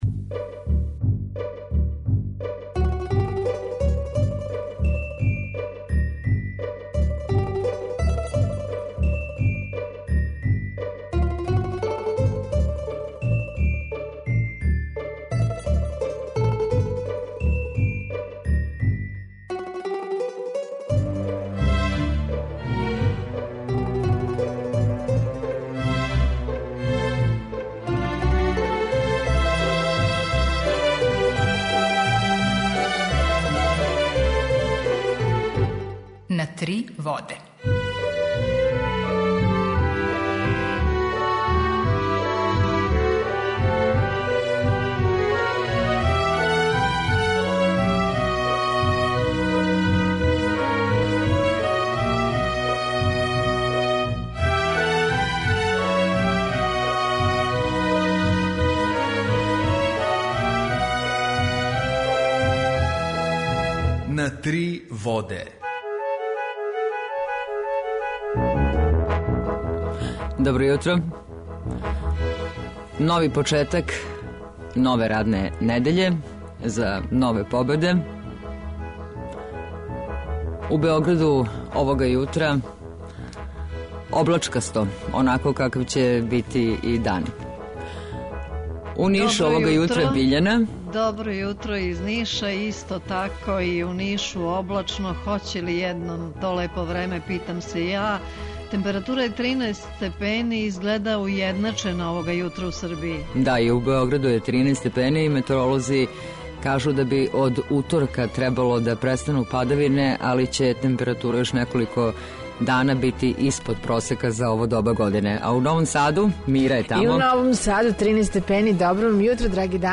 Jутарњи програм заједнички реализују Радио Београд 2, Радио Нови Сад и дописништво Радио Београда из Ниша.
У два сата биће и добре музике, другачије у односу на остале радио-станице.